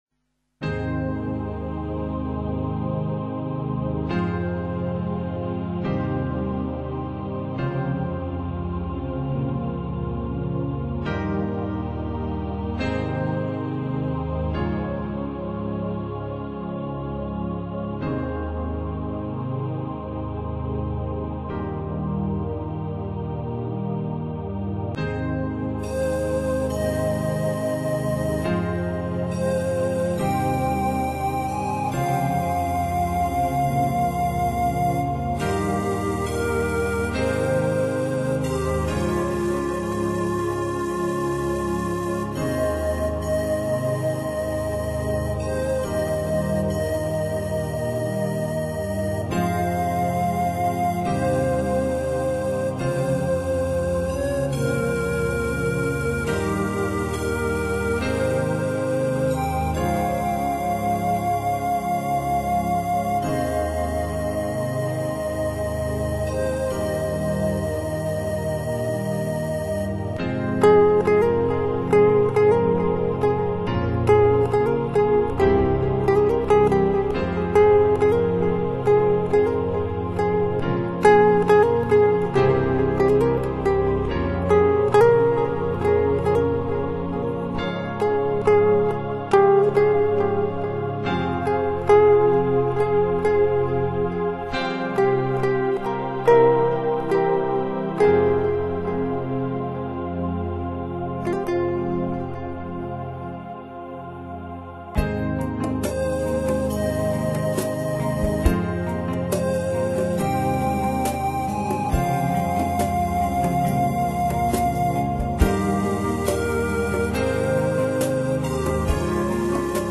木管和打击乐以外，更是在那种玄妙里加入了吉他~~~而不得不提的是~~和声的美妙也依然是最令人迷醉的~~~~